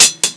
hihat (Dreams - Dreams2).wav